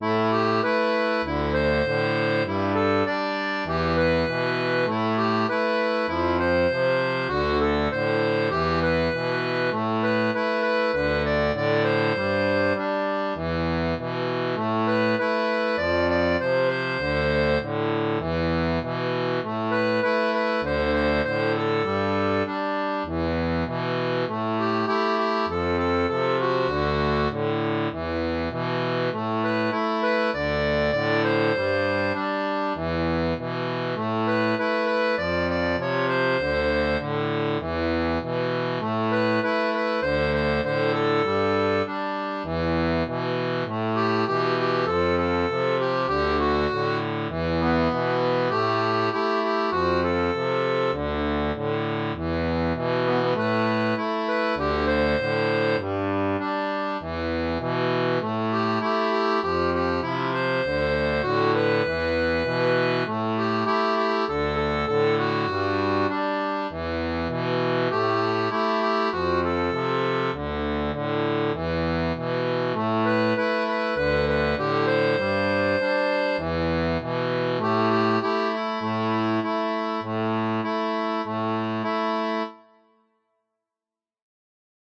Chant de marins